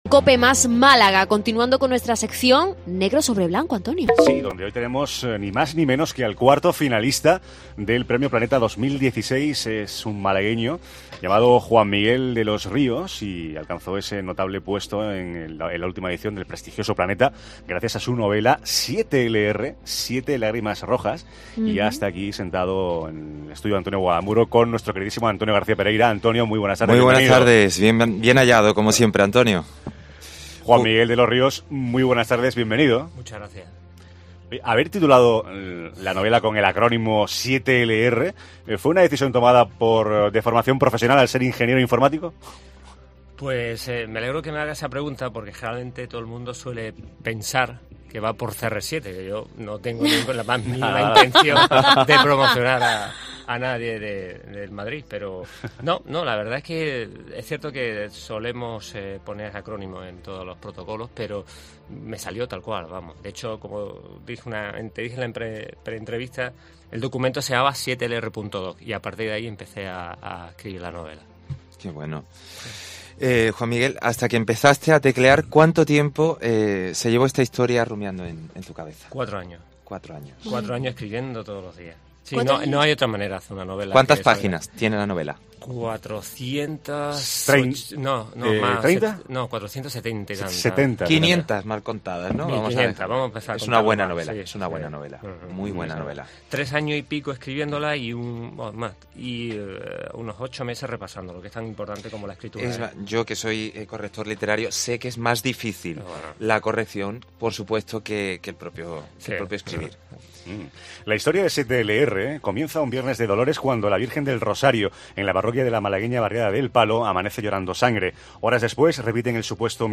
Entrevista Cope
Entrevista-Cope.mp3